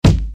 Kicks
nt good kick 1.wav